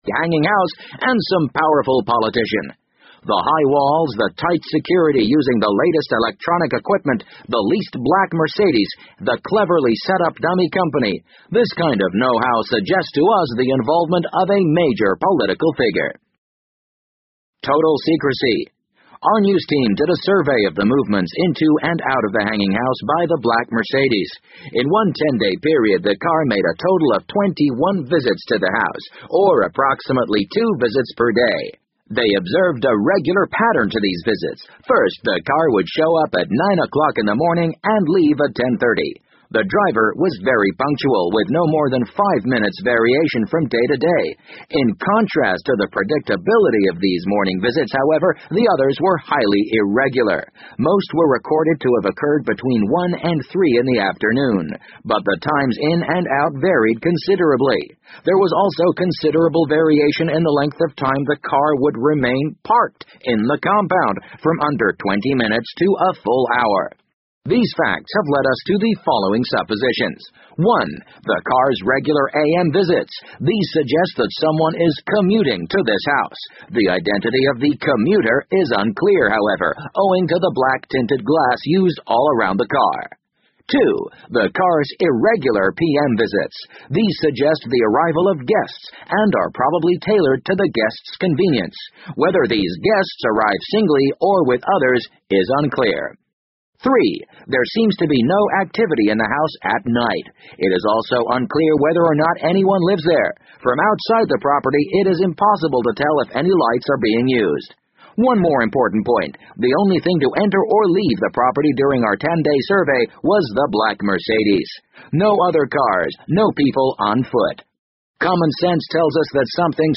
BBC英文广播剧在线听 The Wind Up Bird 012 - 14 听力文件下载—在线英语听力室